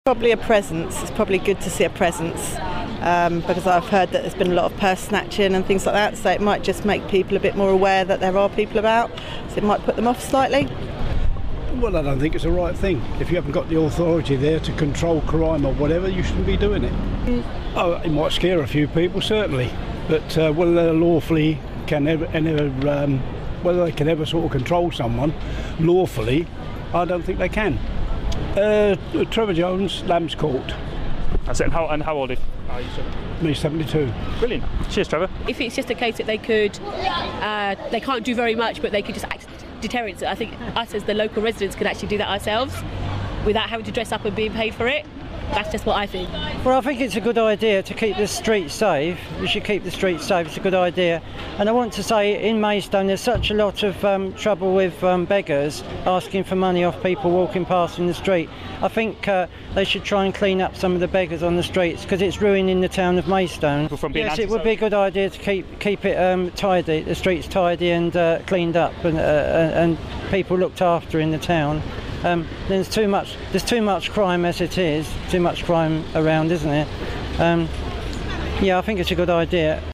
LISTEN: What People in Maidstone Think About New Town Marshals